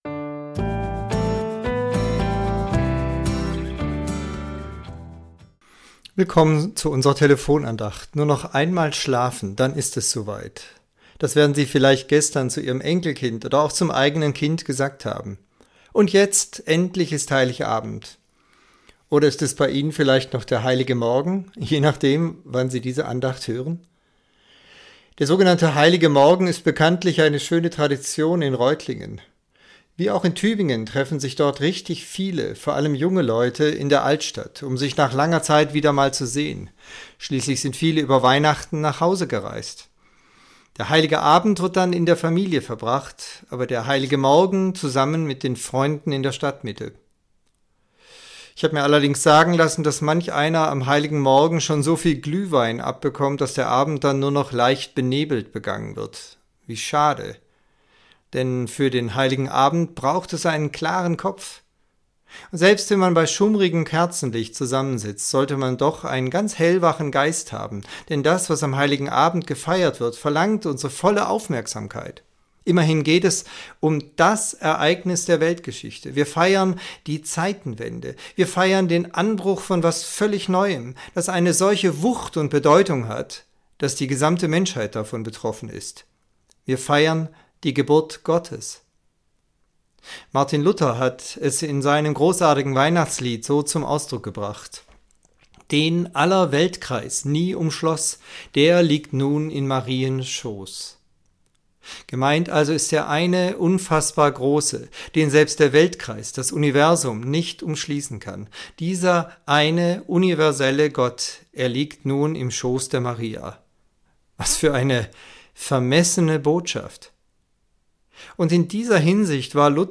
Andacht zur Weihnachtswoche Teil 5